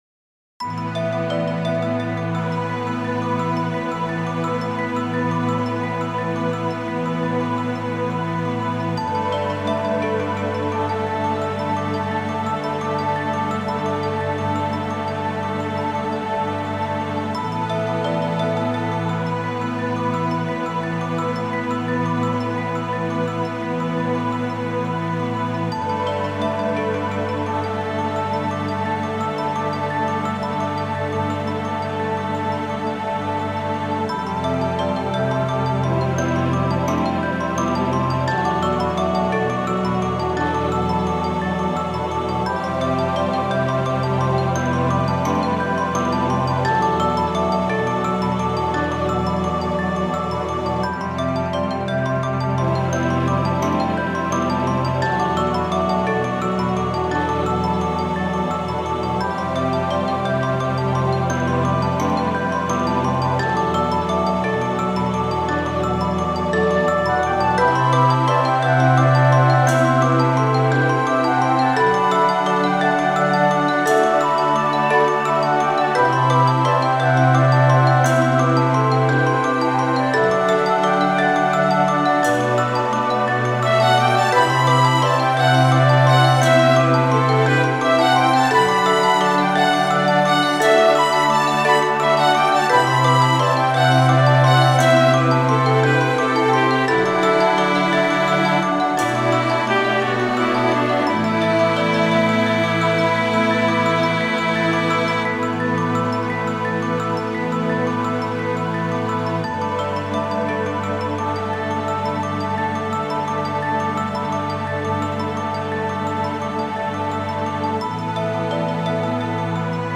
Game Music
game ost remastered music retro